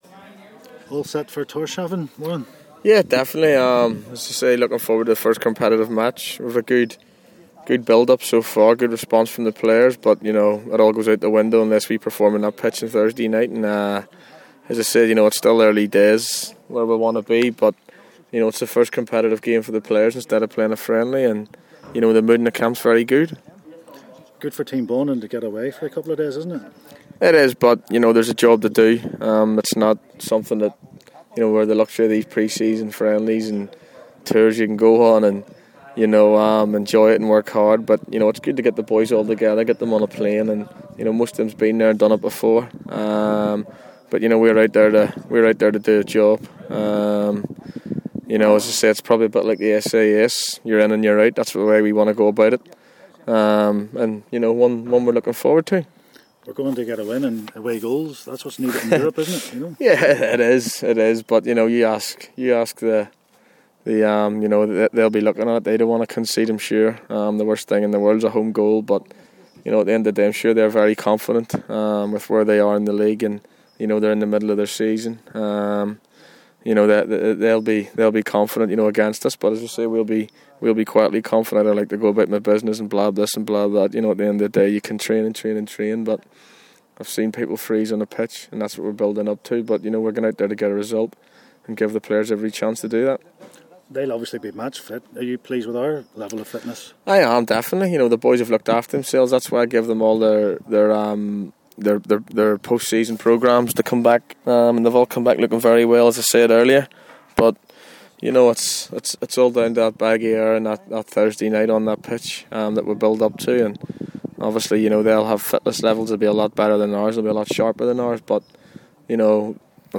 Warren Feeney in conversation